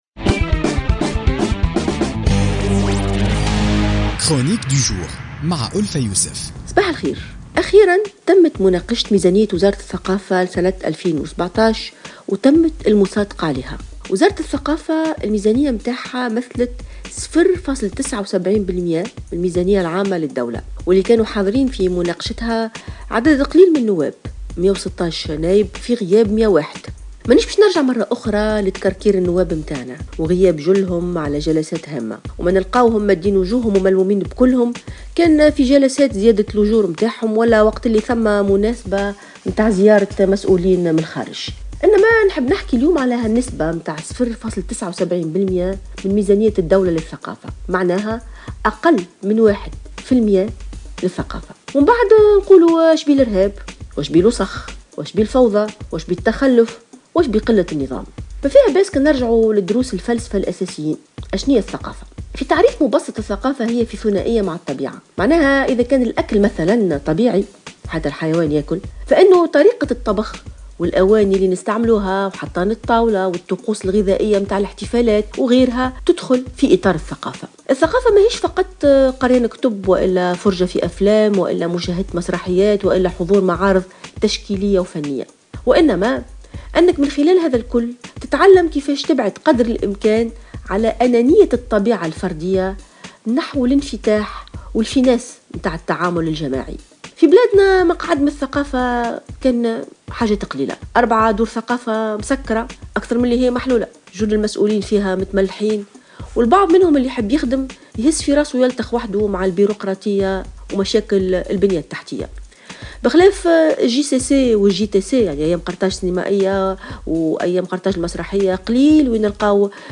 تطرقت الكاتبة ألفة يوسف في افتتاحية اليوم الجمعة 2 نوفمبر 2016 إلى ميزانية وزارة الثقافة لسنة 2017 والمصادقة عليها والتي تمثل ميزانيتها 0.79 بالمائة من الميزانية العامة للدولة وسط حضور ضعيف للنواب في جلسة المناقشة.